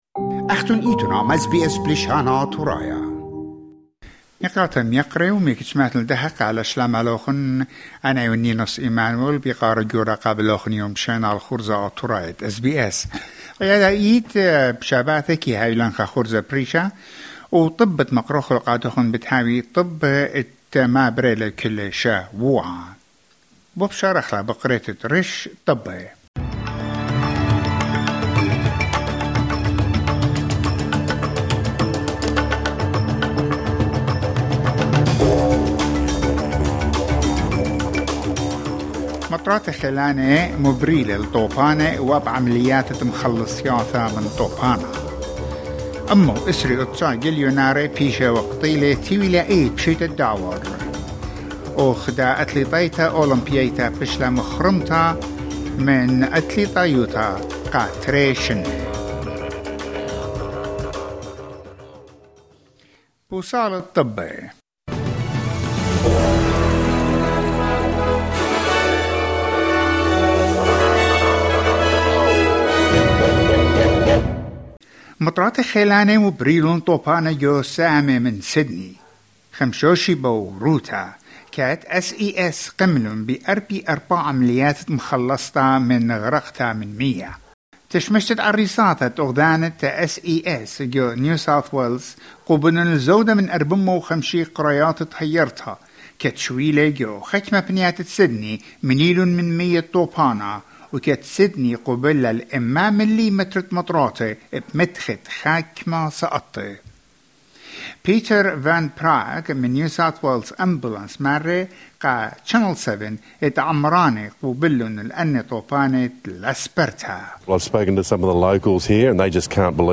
Weekly news wrap in Assyrian